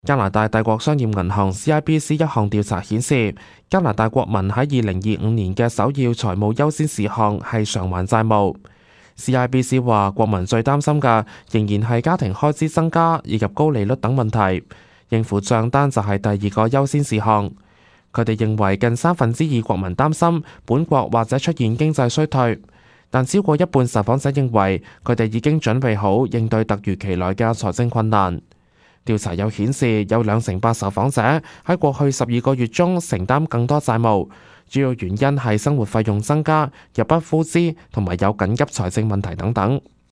news_clip_21933.mp3